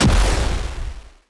Explosion0003.ogg